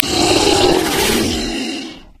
255081e1ee Divergent / mods / Soundscape Overhaul / gamedata / sounds / monsters / bloodsucker / die_0.ogg 16 KiB (Stored with Git LFS) Raw History